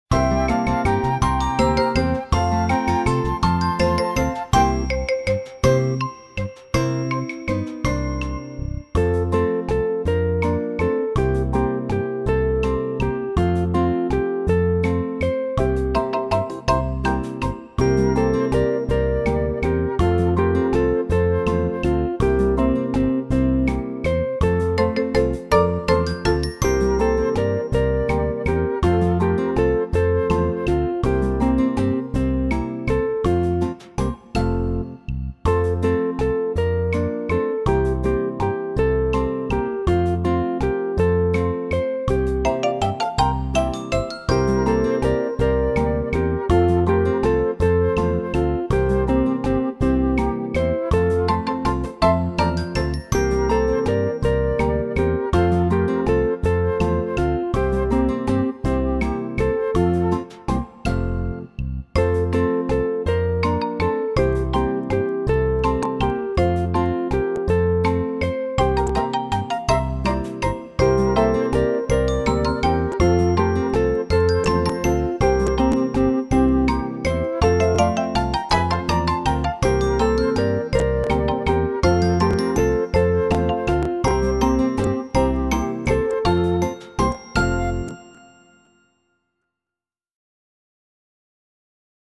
27 Wiosna tuż tuż akomp.mp3